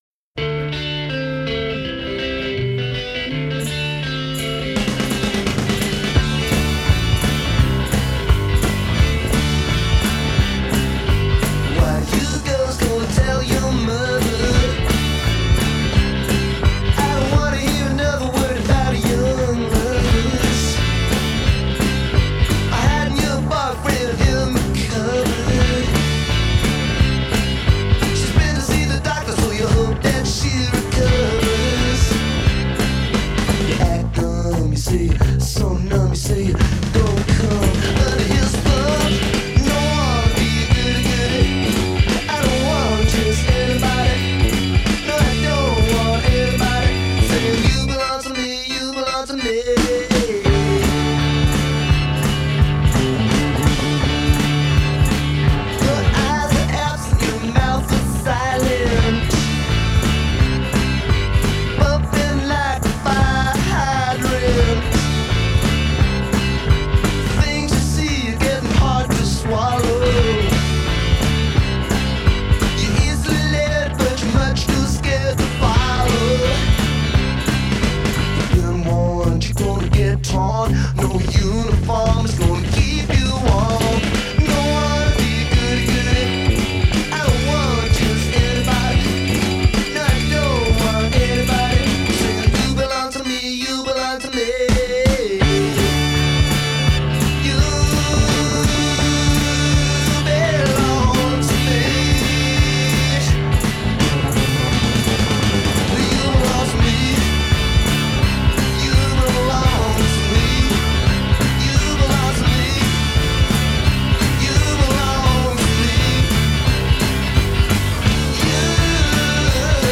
the catchy lead guitar line